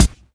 Kick OS 21.wav